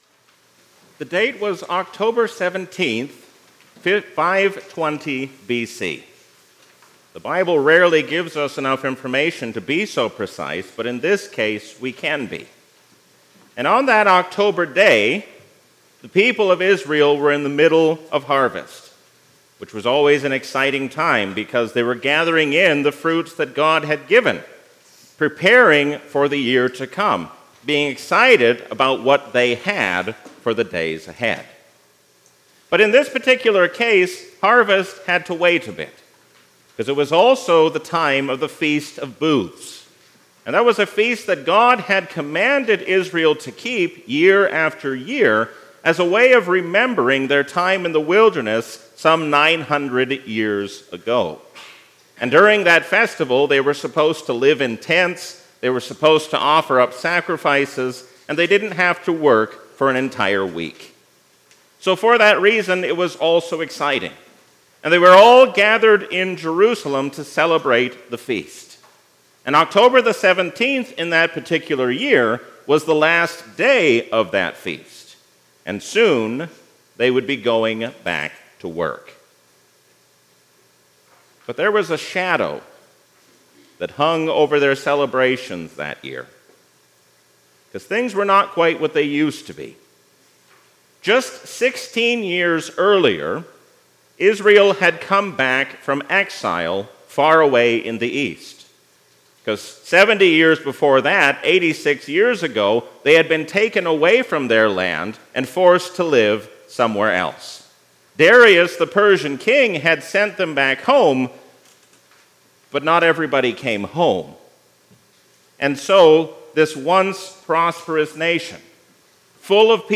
A sermon from the season "Trinity 2024."